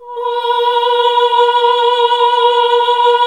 AAH B2 -L.wav